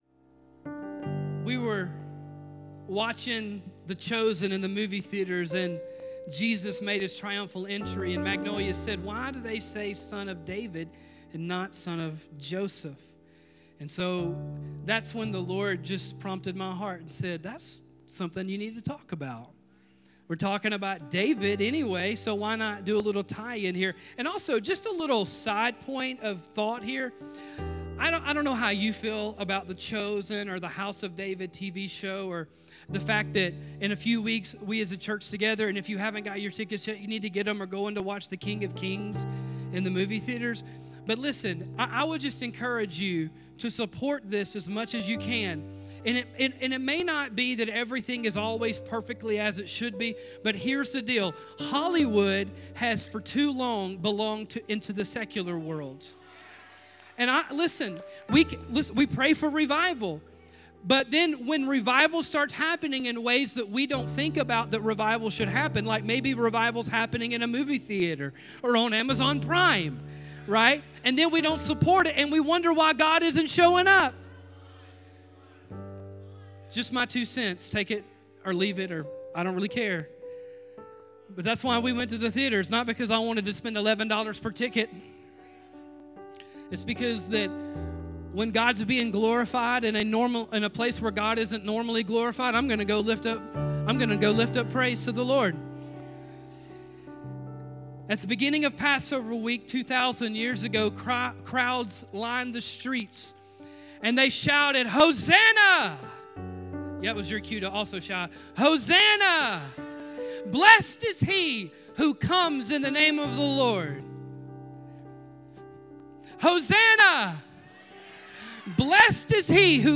Join us in this message based on Matthew 16.